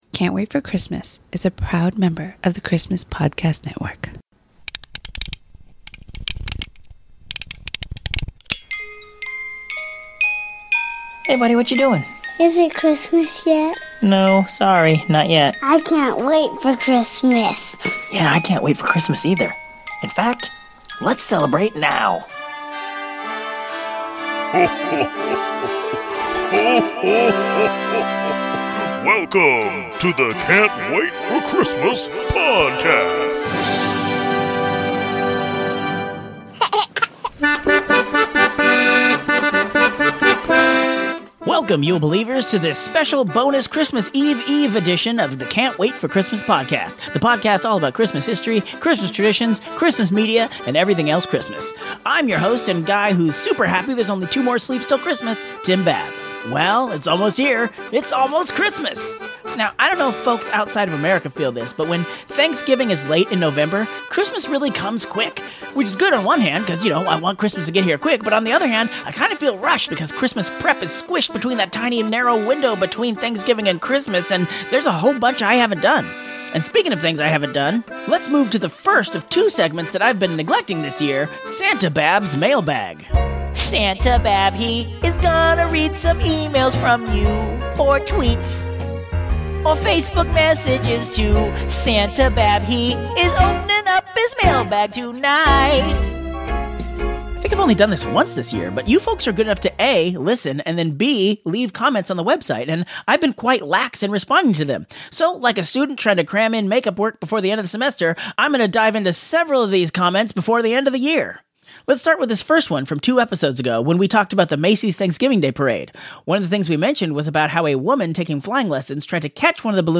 On this bonus episode, we respond to some listener comments, investigate a disturbing poll about waning interest in Christmas traditions, and sing a “Wicked” new parody song.